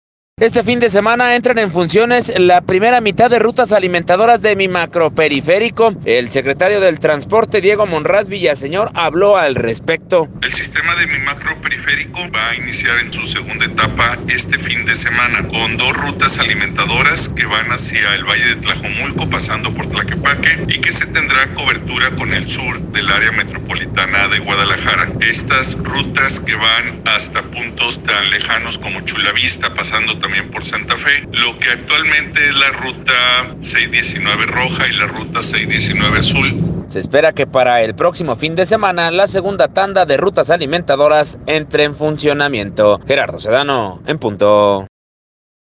Este fin de semana entran en funciones, la primera mitad de rutas alimentadoras de mi Macro Periférico, el Secretario de Transporte, Diego Monraz Villaseñor, habló al respecto.